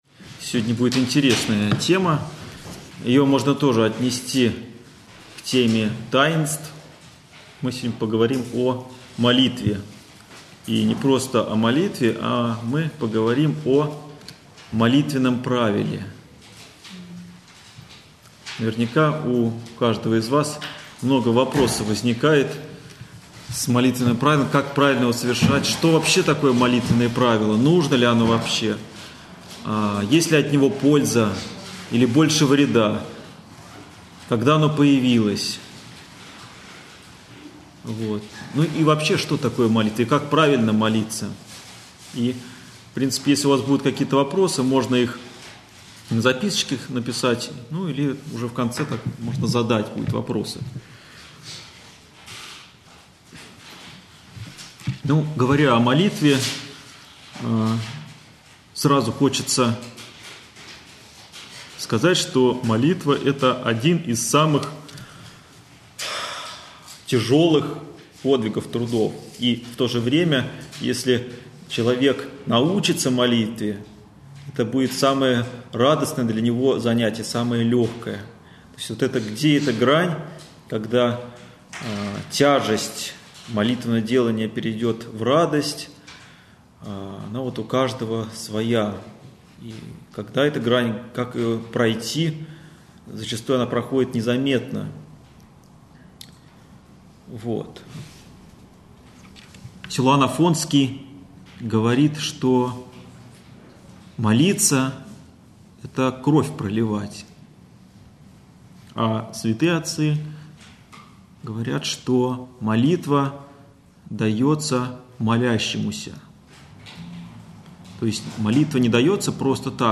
лекция
Общедоступный православный лекторий 2013-2014